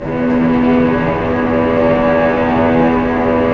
Index of /90_sSampleCDs/Roland LCDP08 Symphony Orchestra/STR_Vcs Bow FX/STR_Vcs Sul Pont